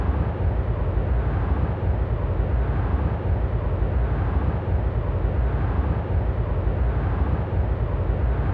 Propagation effects in the synthesis of wind turbine aerodynamic noise | Acta Acustica
Test cases C: xR = 500 m, medium turbulence and grass ground in summer.